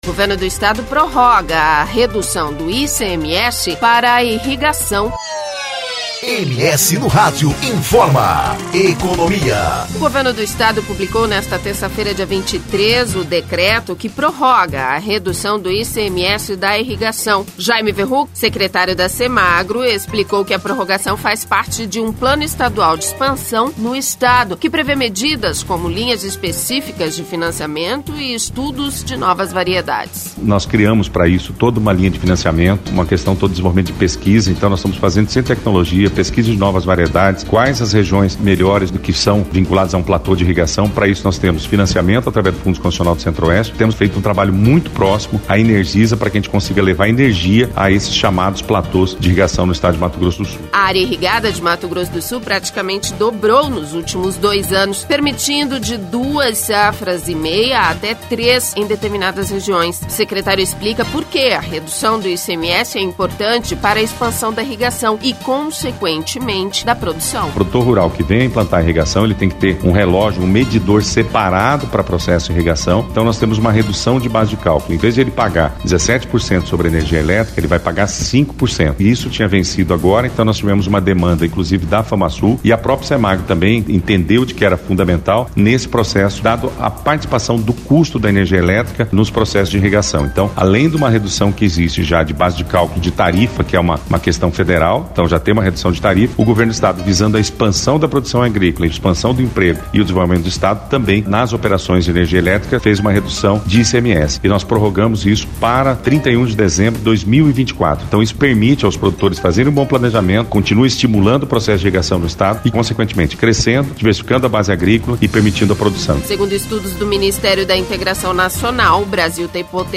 O secretário explica porque a redução do ICMS é importante para a expansão da irrigação, e consequentemente da produção.